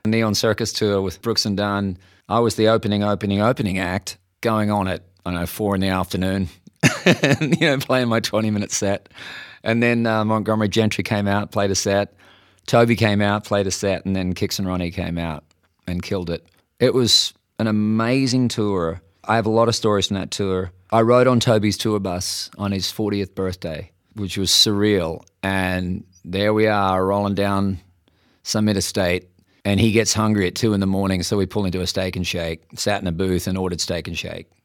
Keith Urban recalls being on the Brooks & Dunn Neon Circus Tour with Toby Keith and stopping for a late-night snack.